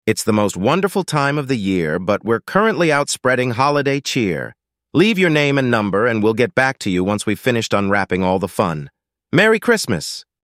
Holiday voicemail greetings
Christmas-voicemail.mp3